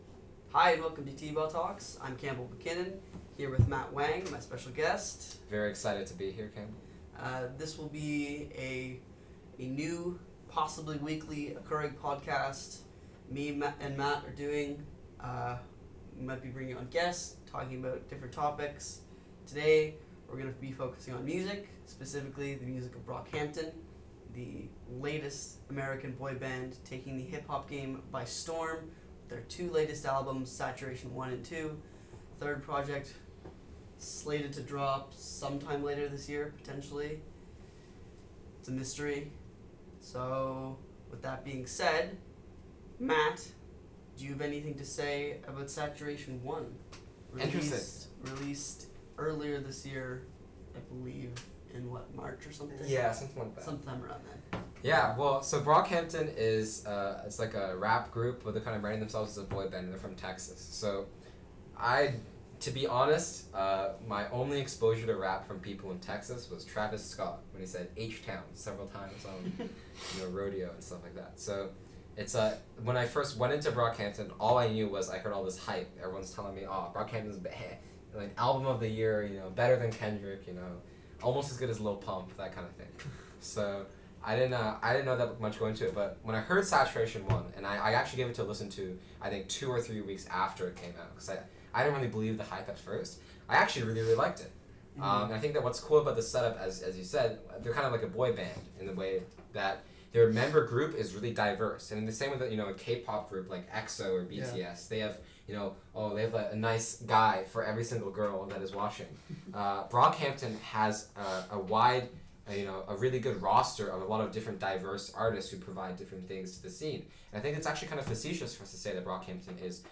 The audio quality has also been improved for later episodes.)